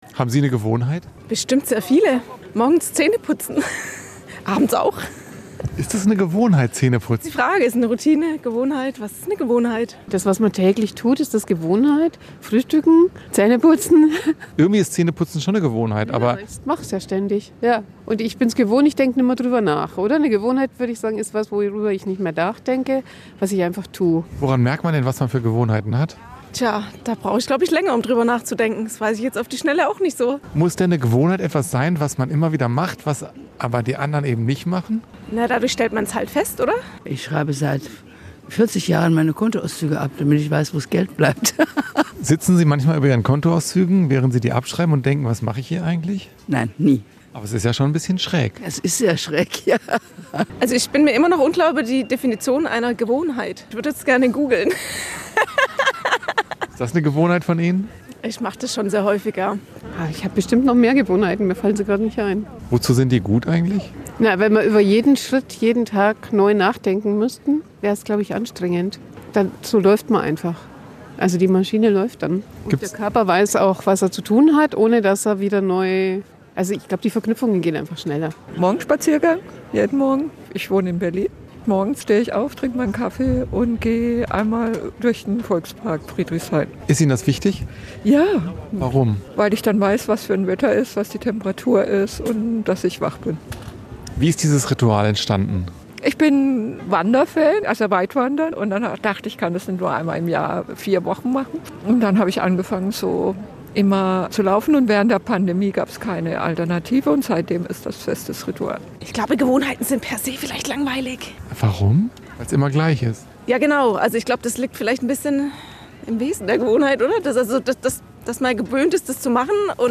Menschen erzählen von ihren Gewohnheiten
Da herrscht die richtige Balance zwischen belebt und ruhig.